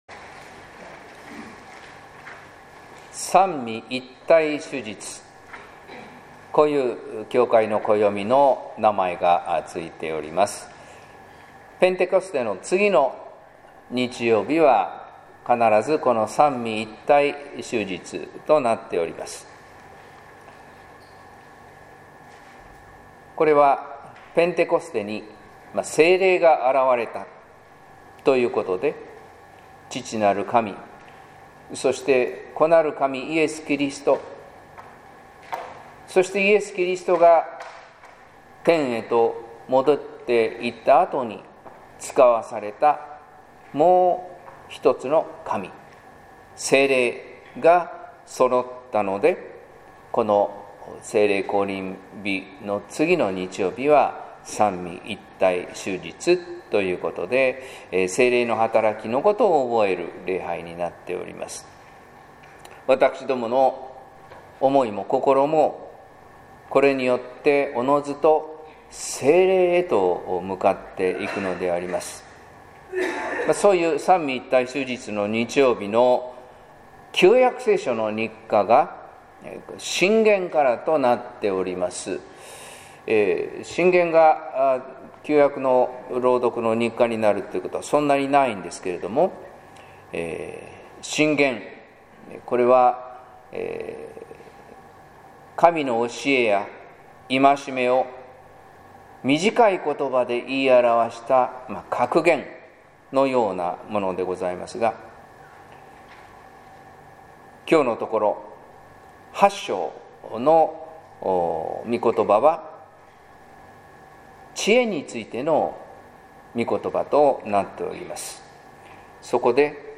説教「聖霊が受け、告げたこと」（音声版） | 日本福音ルーテル市ヶ谷教会